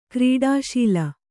♪ krīḍā śila